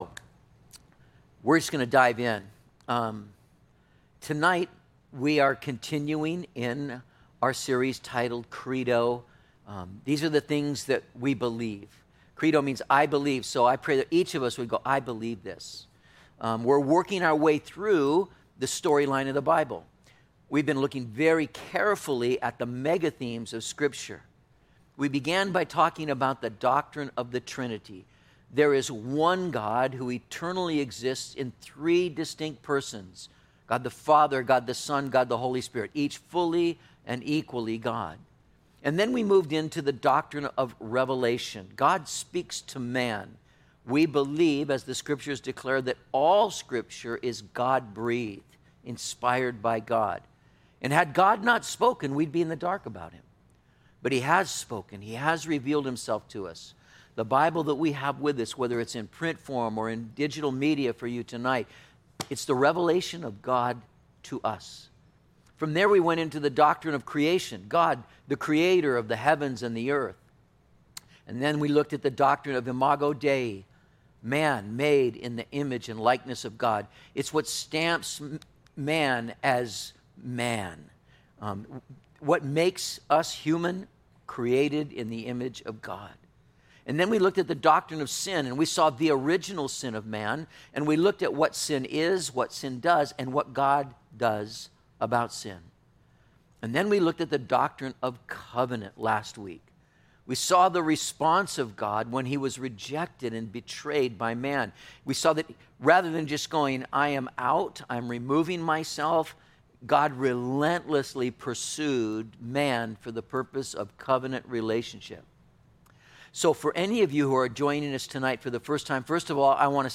07/27/20 Incarnation - Metro Calvary Sermons